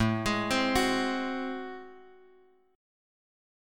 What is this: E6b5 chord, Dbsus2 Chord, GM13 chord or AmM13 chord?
AmM13 chord